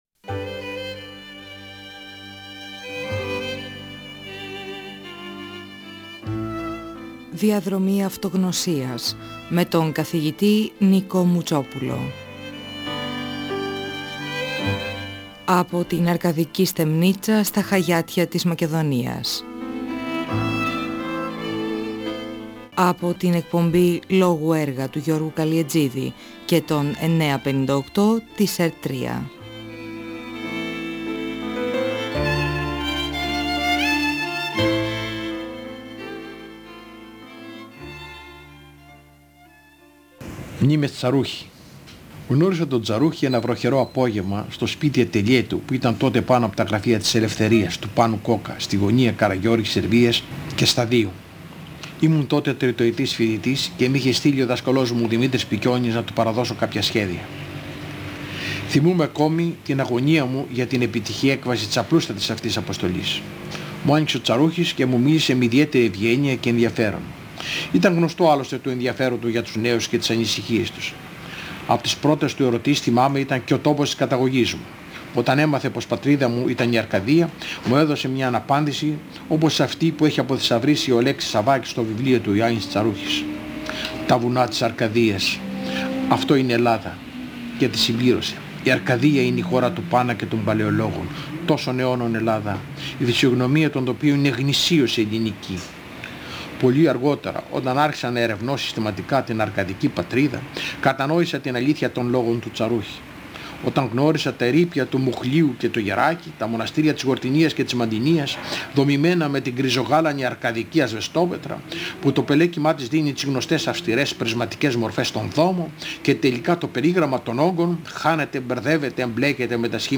Ο αρχιτέκτονας Νικόλαος Μουτσόπουλος (1927–2019) μιλά για τον Γιάννη Τσαρούχη. Την επίσκεψη στο σπίτι του, τον τρόπο διδασκαλίας του, τη σχέση Τσαρούχη και Εγγονόπουλου.